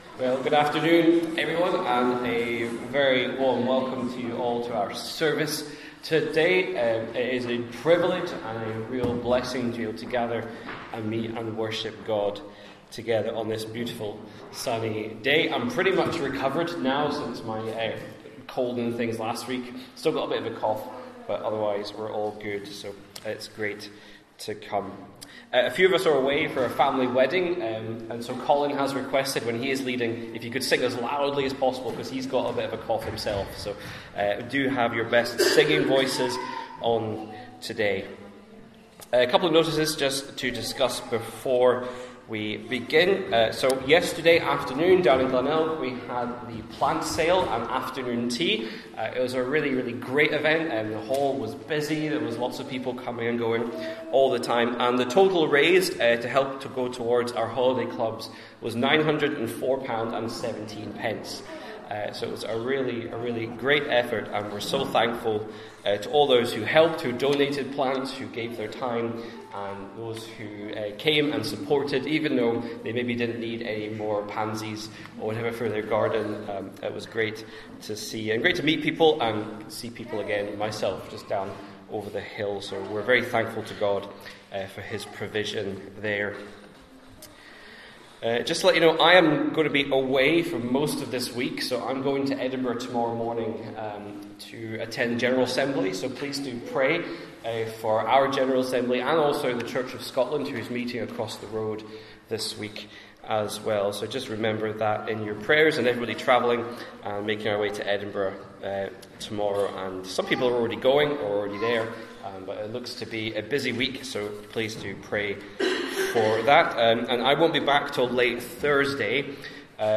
12-Noon-Service-1-2.mp3